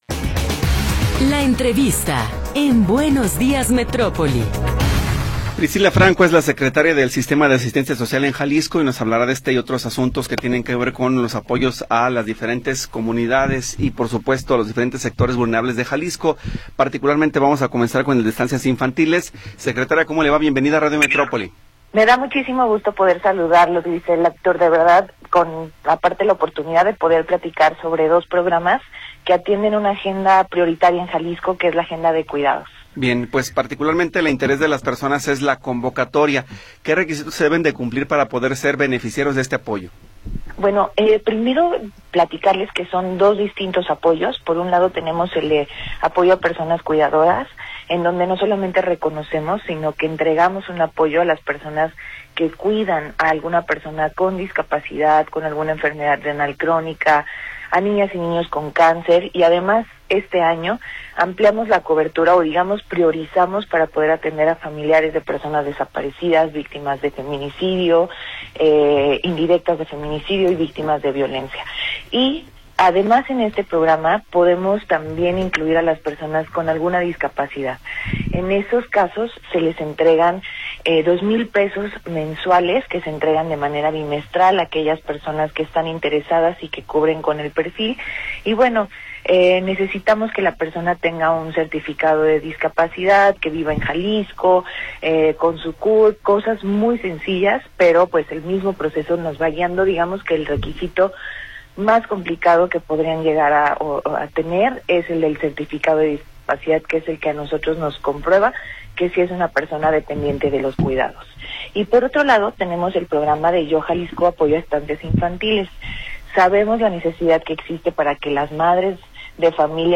Entrevista con Priscilla Franco Barba